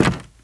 STEPS Wood, Creaky, Walk 21.wav